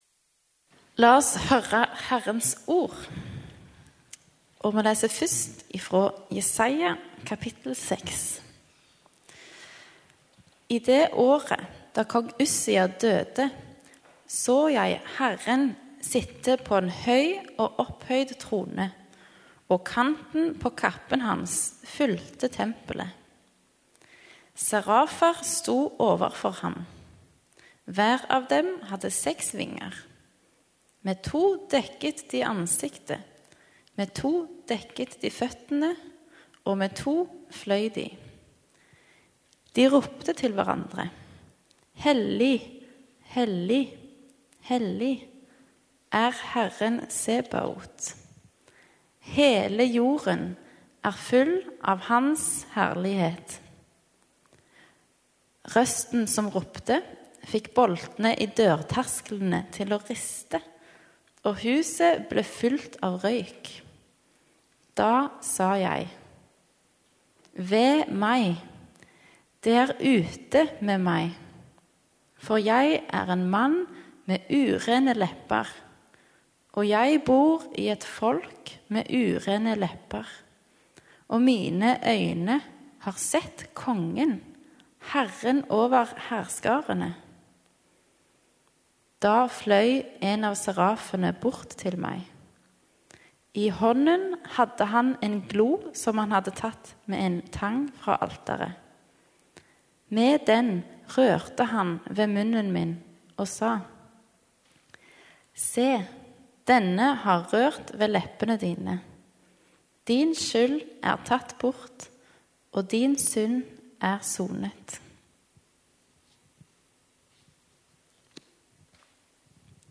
Gudstjeneste 26. september 2021,- møte Gud i ærefrykt | Storsalen